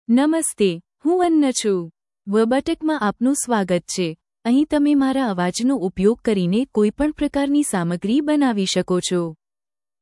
FemaleGujarati (India)
Anna is a female AI voice for Gujarati (India).
Voice sample
Listen to Anna's female Gujarati voice.
Anna delivers clear pronunciation with authentic India Gujarati intonation, making your content sound professionally produced.